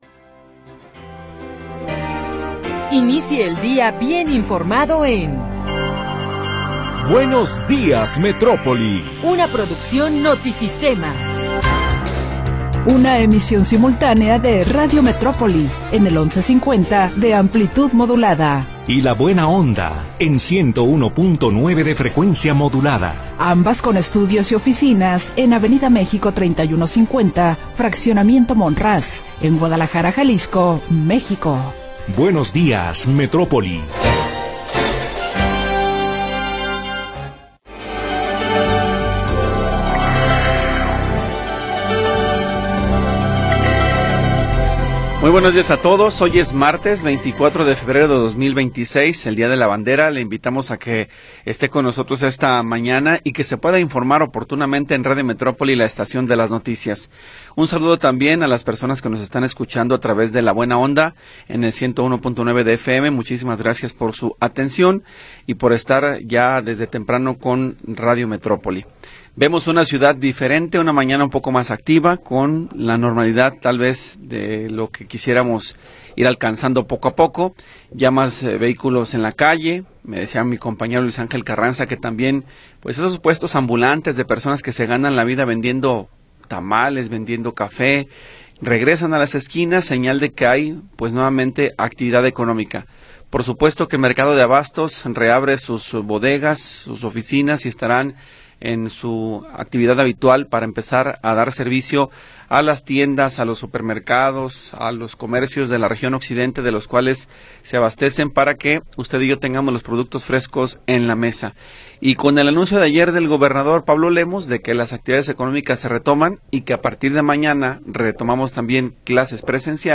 Primera hora del programa transmitido el 24 de Febrero de 2026.